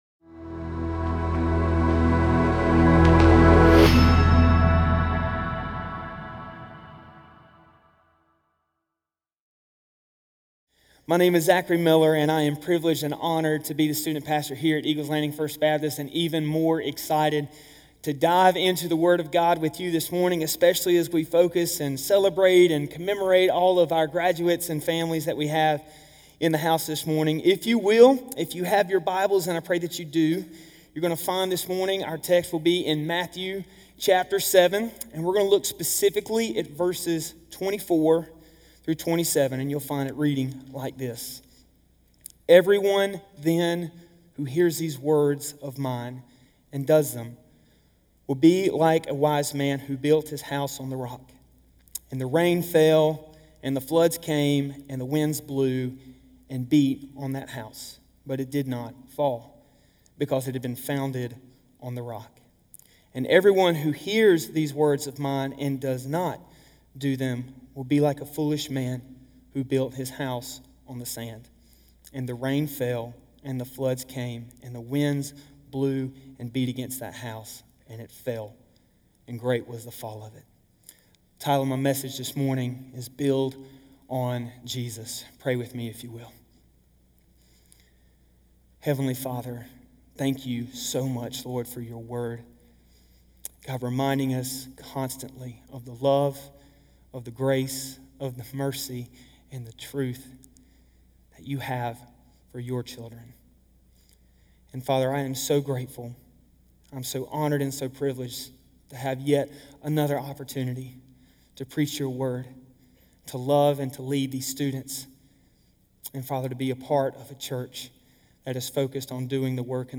Eagle's Landing Sermons Podcast - Build on Jesus | Free Listening on Podbean App